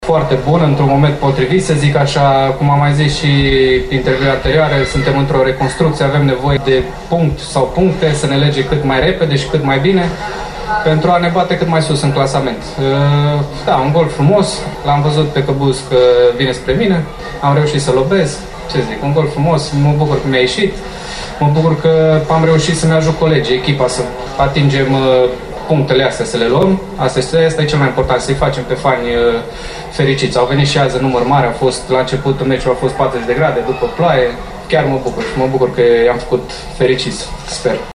La declarațiile ”la cald”